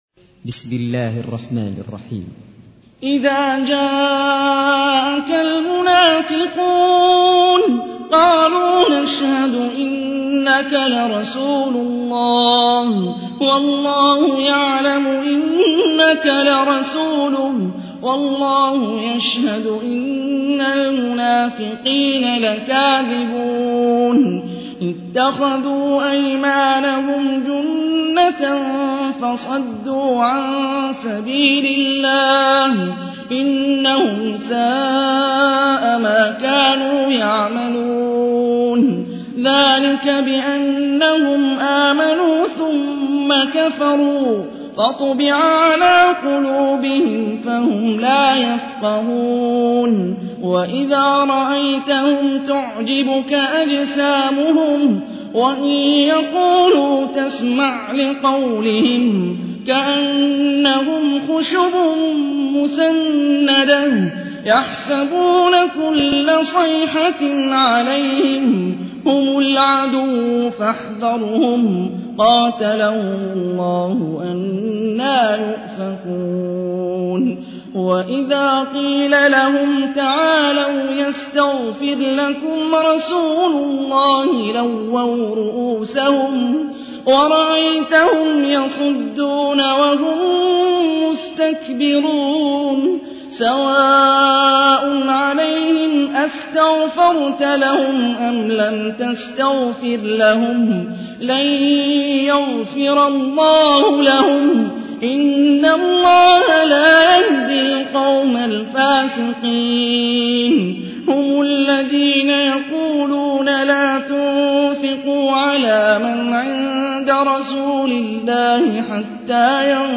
تحميل : 63. سورة المنافقون / القارئ هاني الرفاعي / القرآن الكريم / موقع يا حسين